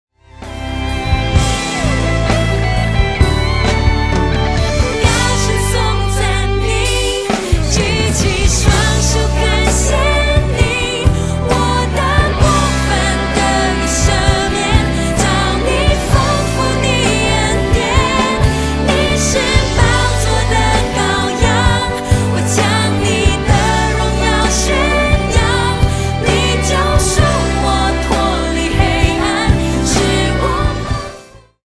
Mandarin Worship Album